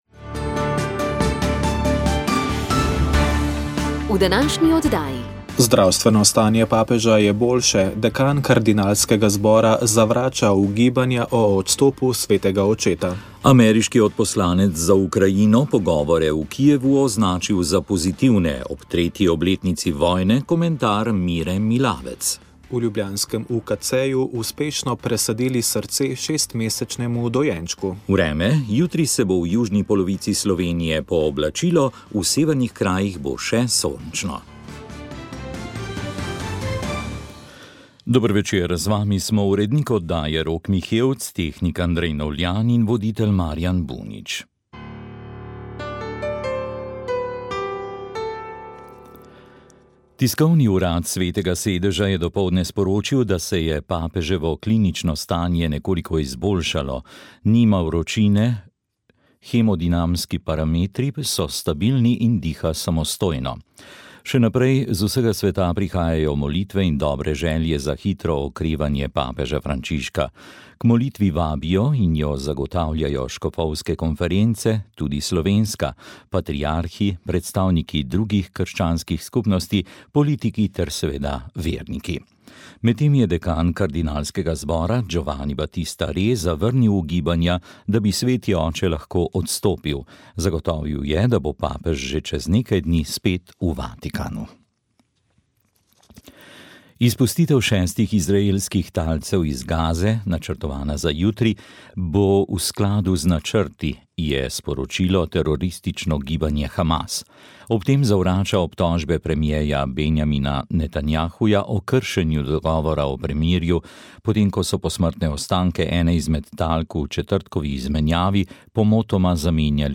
Radio Ognjišče info novice 2. 7. 2022 Utrip dneva dne 2. 7.